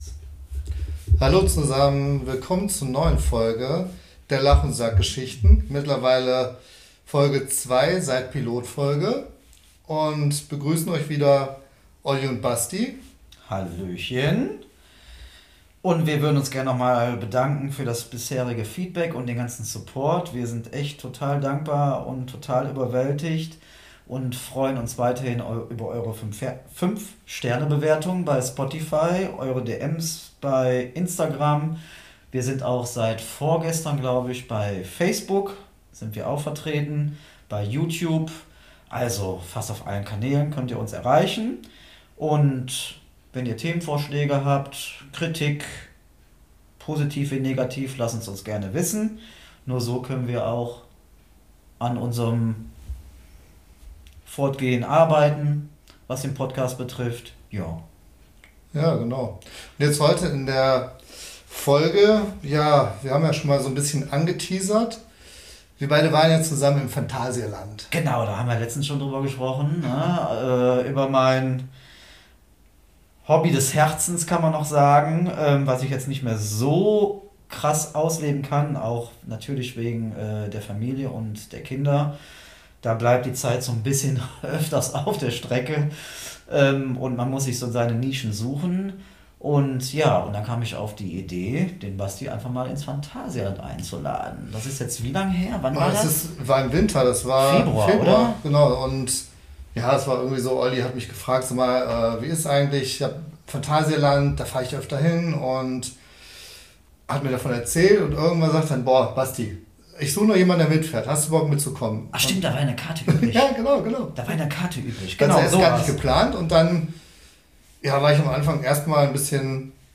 Beschreibung vor 5 Monaten In dieser Folge nehmen wir Euch mit auf eine phantastische Reise in magische Welten und erzählen Euch, was zwei junggebliebene Väter mit Peter Pan - Komplex so machen, wenn sie zu zweit im Phantasialand unterwegs sind. Ein Tag voller Adrenalin, Rumgeblödel, abgerundet durch Helge und Grönemeyer-Imitationen.